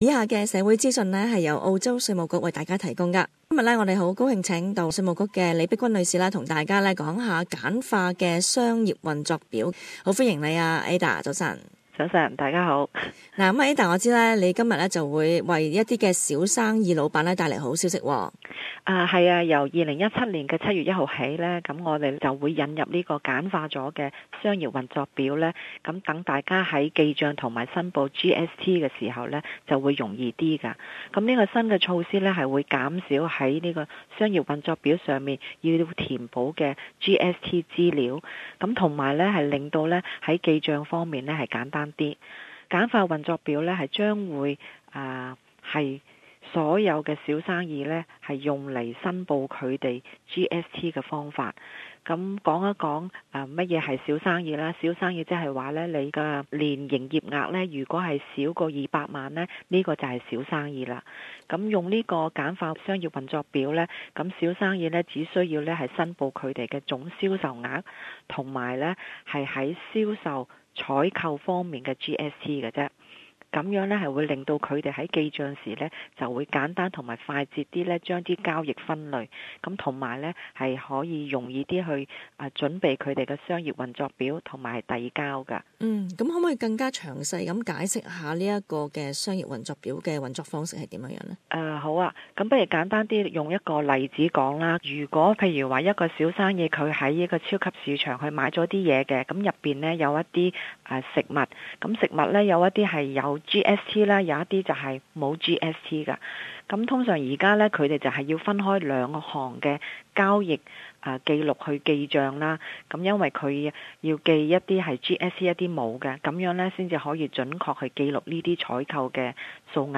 The following community information is brought to you by the Australian Taxation Office. Interview